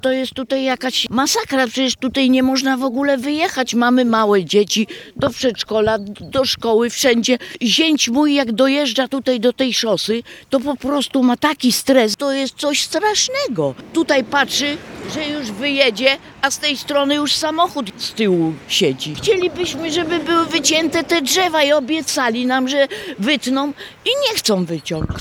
Dziś między Żarowem a Smogolicami koło Stargardu odbył się protest mieszkańców, którzy walczą o poprawę bezpieczeństwa na lokalnej drodze. Ich głównym postulatem jest wycinka drzew ograniczających widoczność przy wjeździe na główną trasę.
Mieszkańcy skarżą się, że codzienna próba wyjazdu na ruchliwą drogę wiąże się z dużym ryzykiem i stresem. Jak mówi jedna z protestujących: